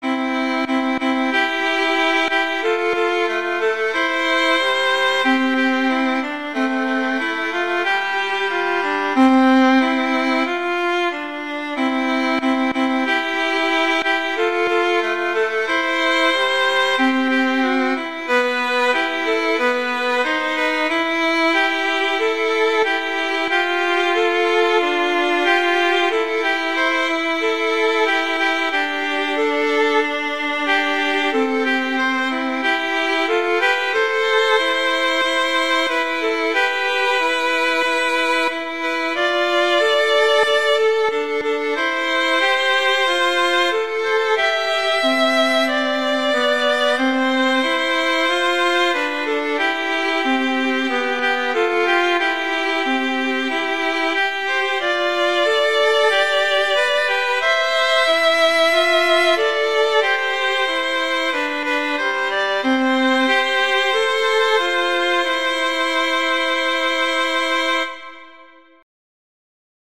Instrumentation: two violins
arrangements for two violins